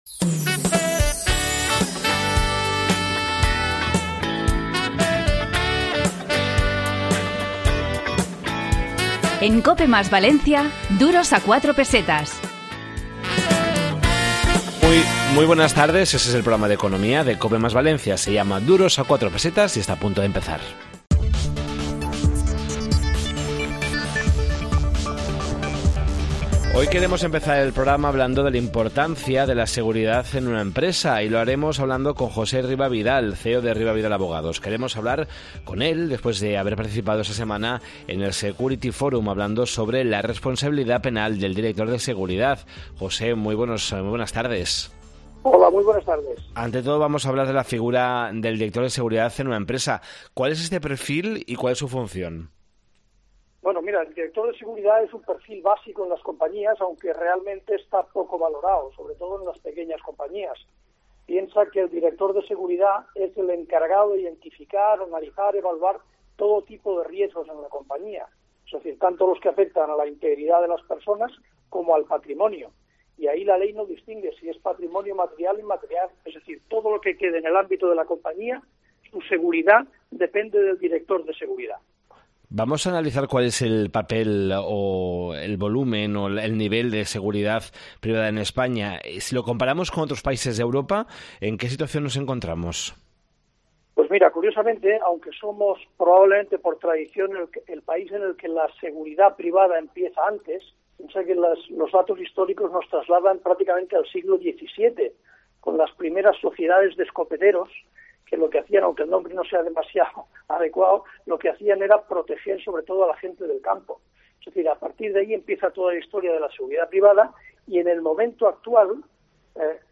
Esta semana, en Duros a 4 Pesetas de COPE + Valencia, en el 92.0 de la FM, hemos preparado un programa dedicado a la figura del director de Seguridad en las empresas, la pobreza energética y las lesiones graves tras cirugías estéticas.